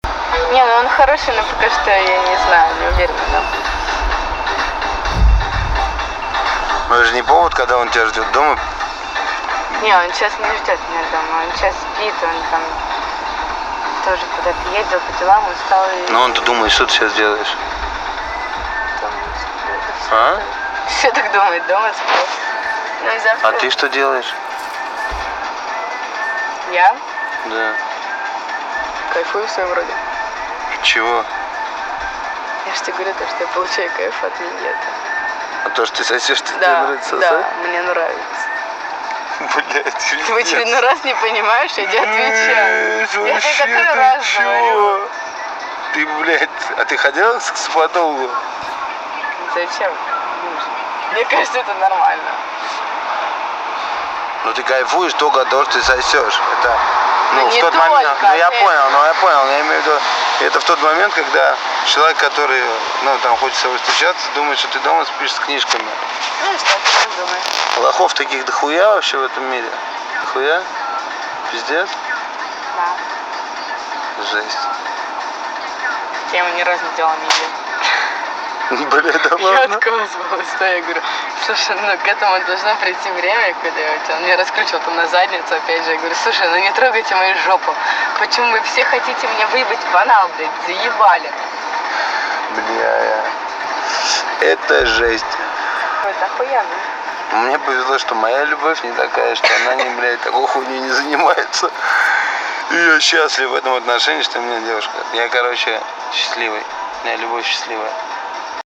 Жанр: Рэп (Хип-хоп)